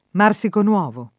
marsico [m#rSiko] etn.; pl. m. ‑ci — sim. il top. f. Marsica (Abr.), il top. Marsico (Bas.: i due paesi di Marsico Vetere [m#rSiko v$tere], meno com. Marsicovetere [id.], in questa grafia anche cogn., e di Marsico Nuovo [